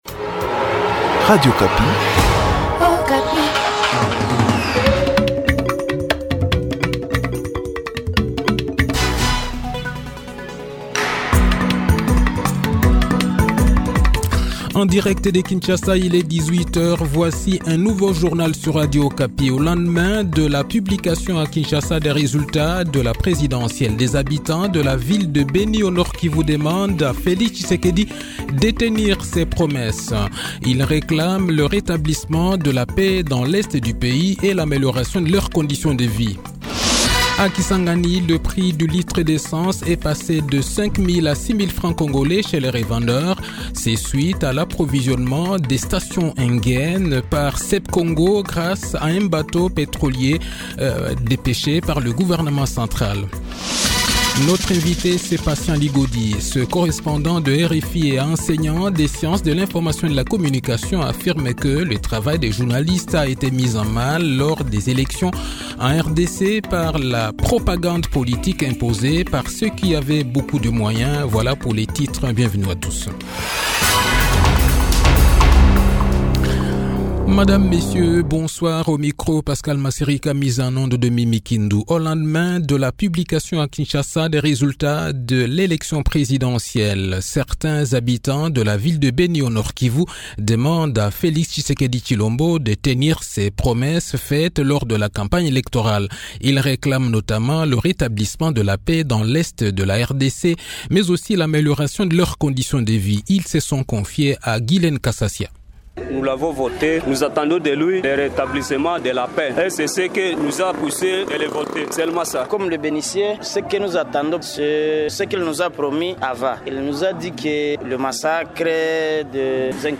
Journal Soir
Le journal de 18 h, 1 janvier 2024